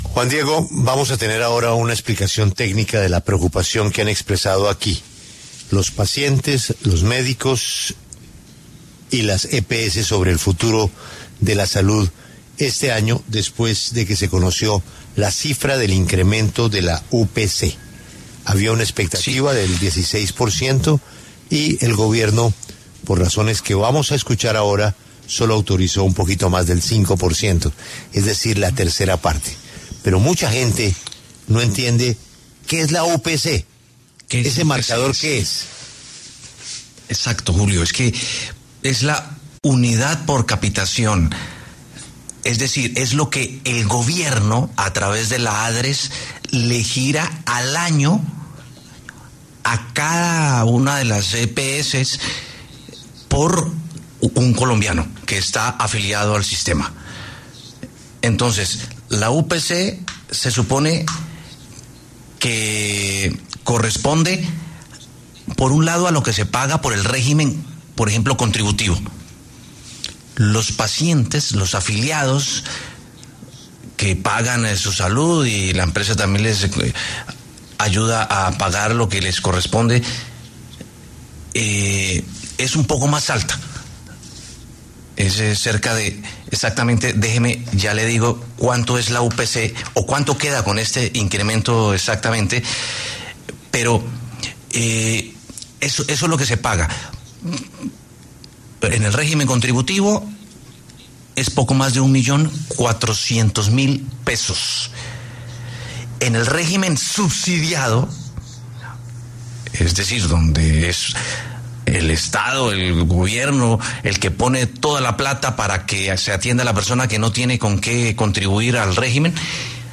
En entrevista con La W, Luis Alberto Martínez, viceministro de Protección Social, explicó los factores que llevaron a este incremento, que se calculó con base en la inflación causada a noviembre de 2024.